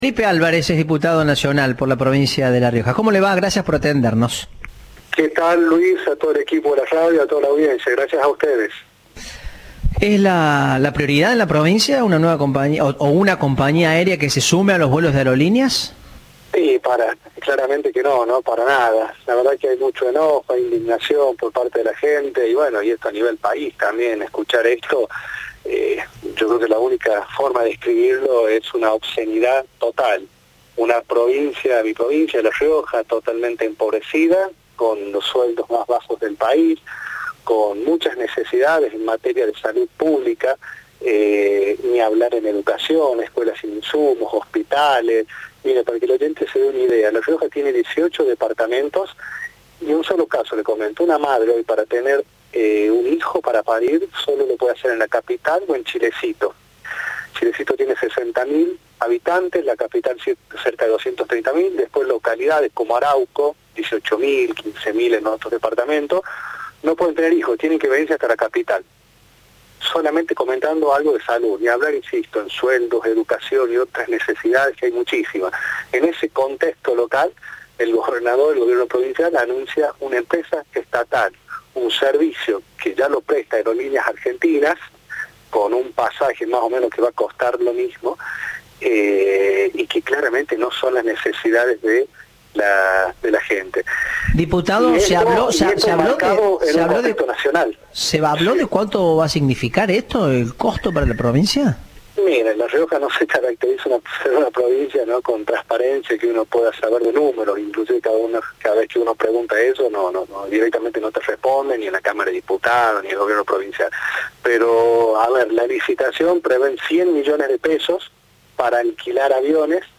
Audio. El diputado riojano Felipe Álvarez cuestionó el nuevo servicio estatal aéreo
Entrevista